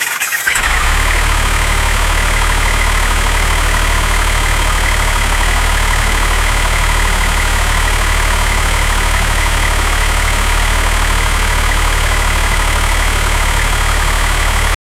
V-type 2-cylinder OHV forced air-cooled engine 1.3 L, 33 PS (24.3 kW; 32.5 bhp), idle, exterior, exhaust 0:30 Created Apr 1, 2025 7:21 AM Engine 2-cylinder, 1.3-liter, air-cooled petrol engine,33 hp @ 3,300 RPM, separated noise and rumble sounds during ride 0:15 Created Jan 21, 2025 2:09 PM
engine-2-cylinder-13-lite-dww6azsy.wav